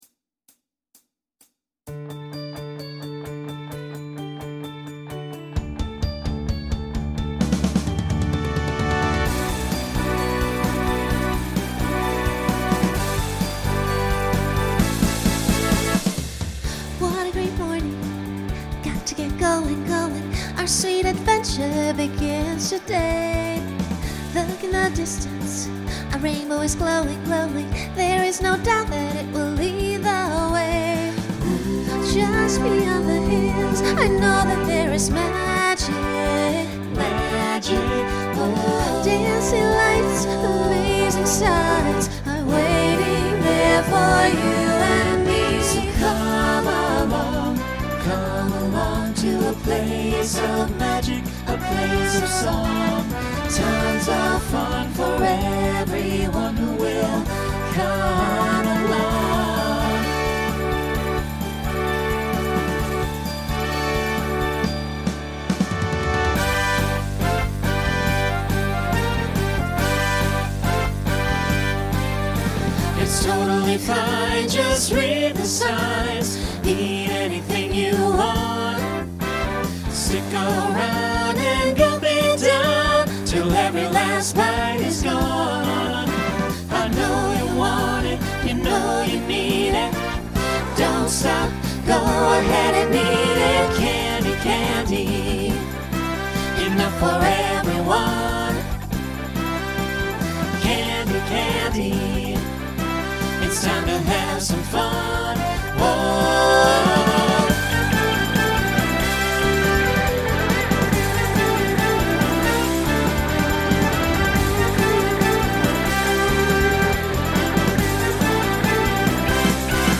Genre Broadway/Film , Pop/Dance Instrumental combo
Voicing SATB